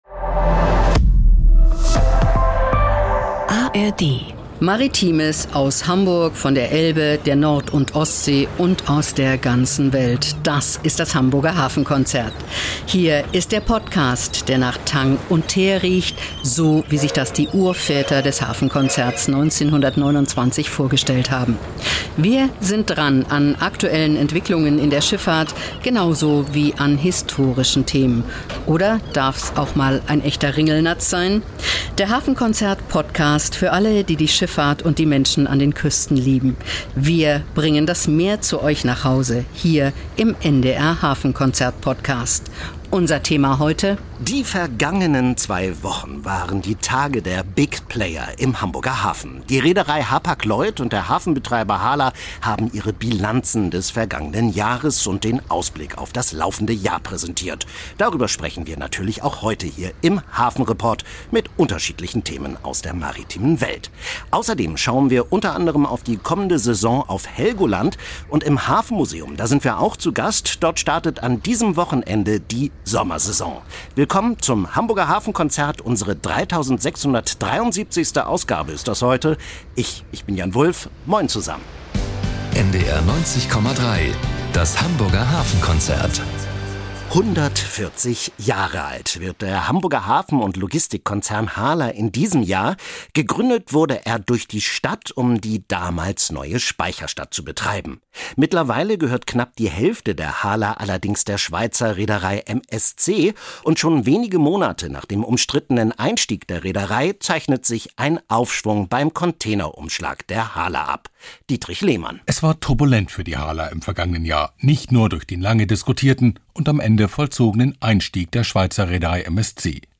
Spannende Reportagen und exklusive Berichte rund um den Hamburger Hafen, die Schifffahrt und die norddeutsche Geschichte.